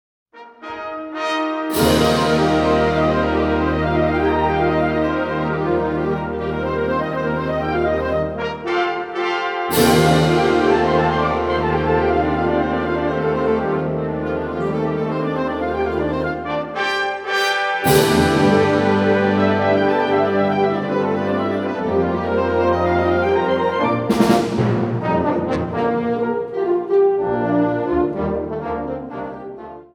Categorie Harmonie/Fanfare/Brass-orkest
Subcategorie Hedendaagse muziek (1945-heden)
Bezetting Ha (harmonieorkest)